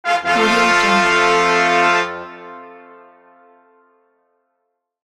Level_Complete_1.mp3